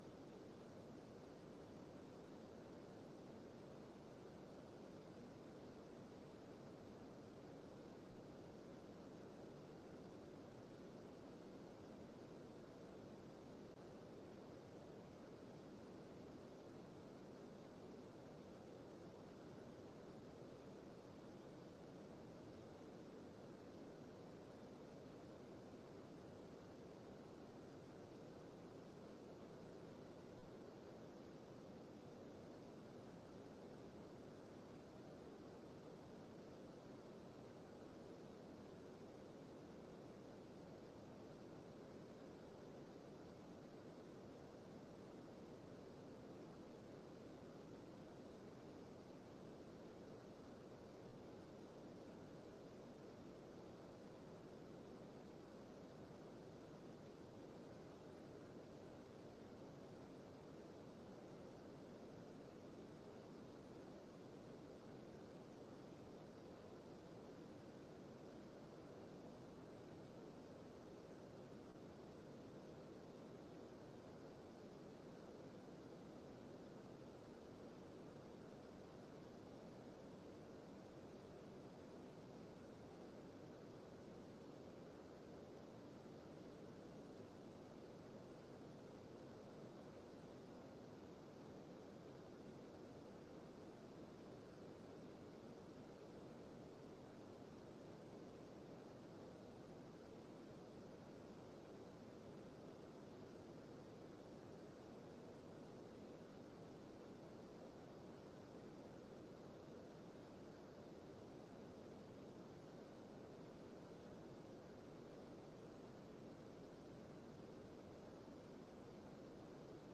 Raadsbijeenkomst 29 november 2023 19:30:00, Gemeente Tynaarlo